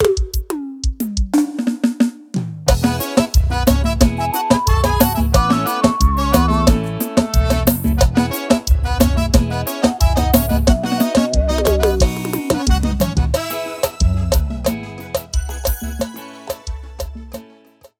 • Demonstrativo Pagode:
• São todos gravados em Estúdio Profissional, Qualidade 100%